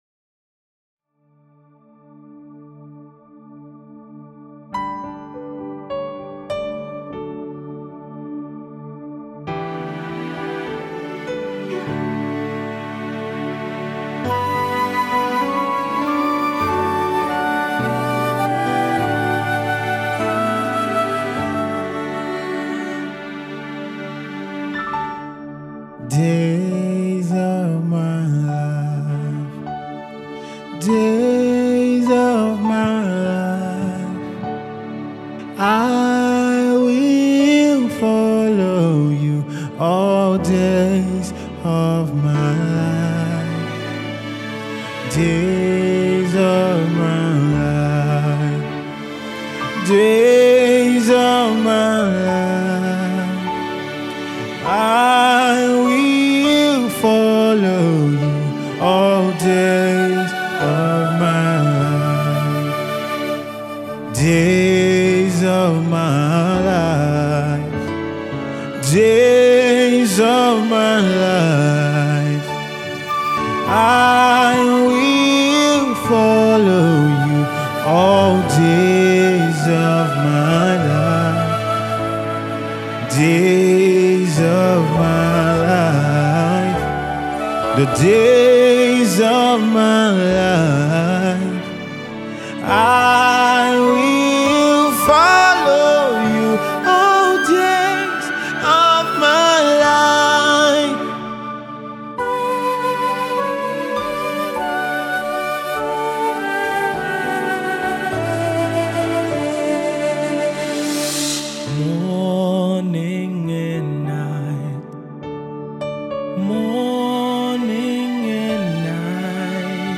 A heartfelt anthem of gratitude and faith